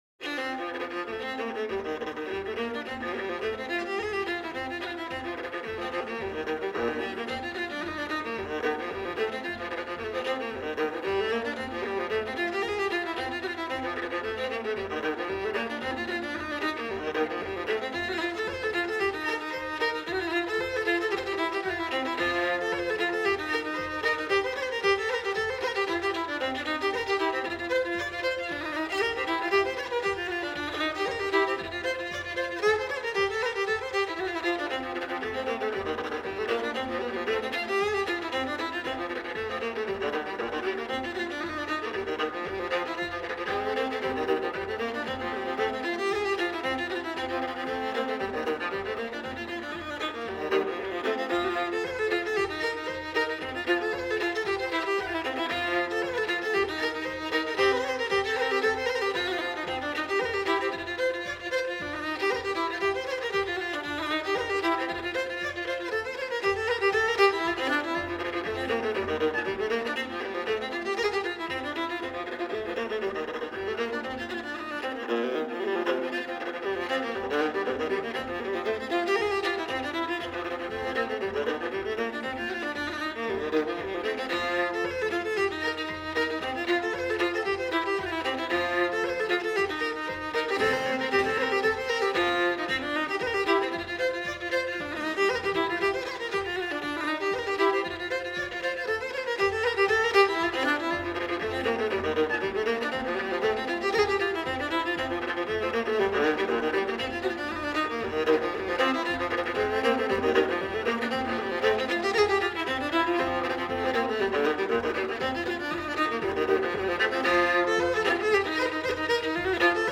风笛、小提琴等乐器逐渐发展出爱尔兰音乐的固有特色。
幸好，这是一张现场录音的演奏会唱片。